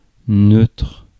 ÄäntäminenFrance (Paris):
• IPA: [nøtʁ]